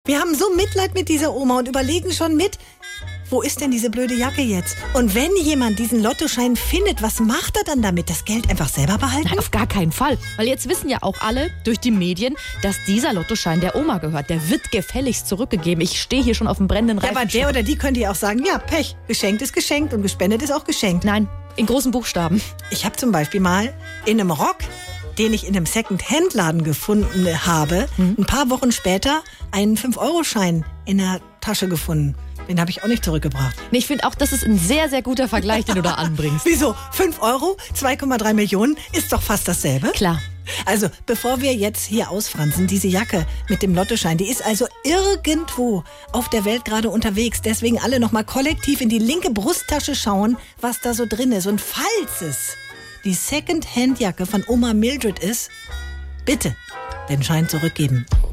Nachrichten Falls ihr die Jacke mit dem Lottoschein habt – bitte zurückgeben!